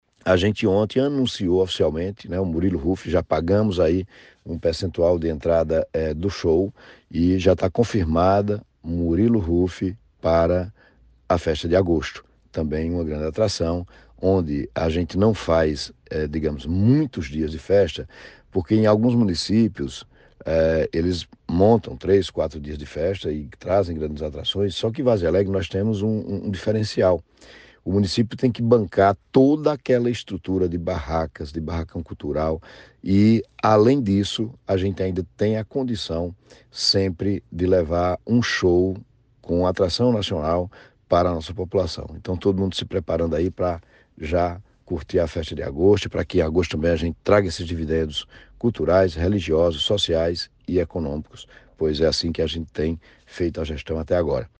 A informação foi confirmada à reportagem pelo prefeito Zé Helder, MDB, na manhã desta sexta-feira, 30.
ZE-HELDER-SOBRE-MURILO.mp3